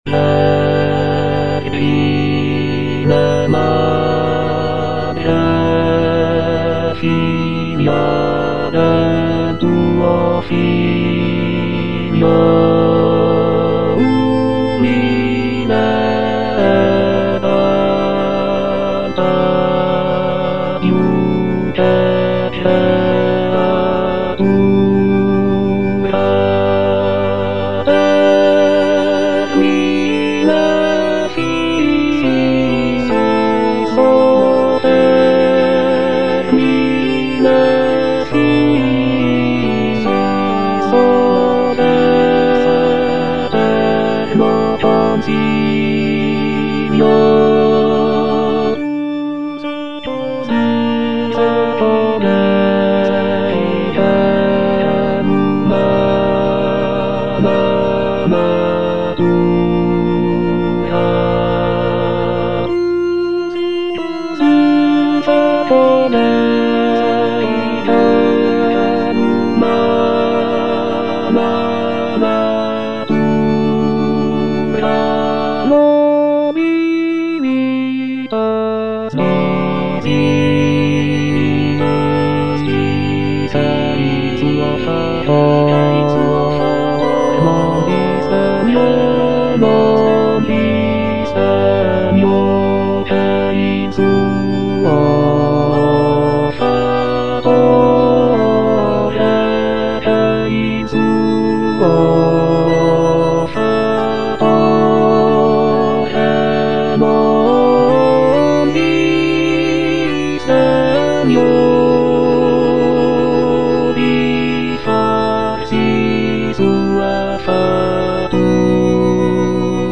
Baritone (Emphasised voice and other voices)
choral work